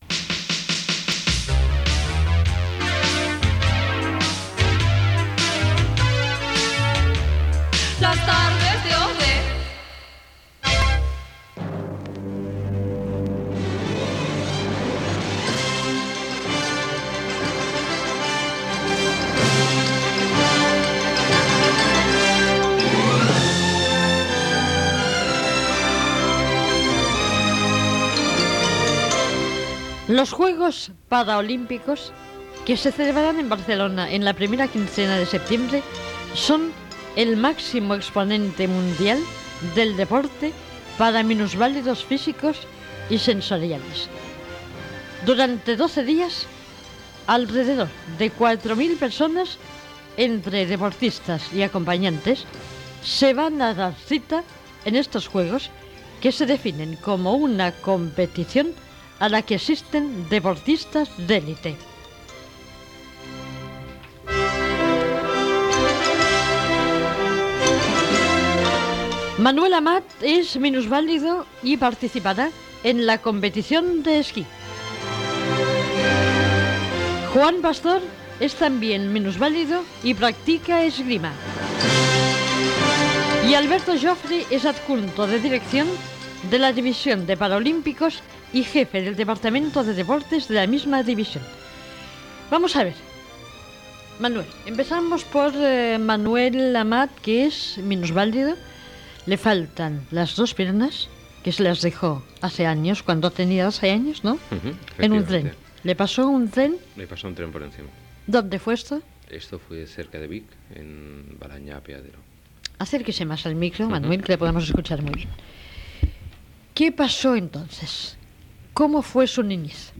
Indicatiu del programa, els Jocs Paralímpics de Barcelona 1992
Entreteniment